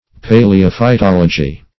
Paleophytology \Pa`le*o*phy*tol"o*gy\
(p[=a]`l[-e]*[-o]*f[imac]*t[o^]l"[-o]*j[y^]), n. [Paleo- +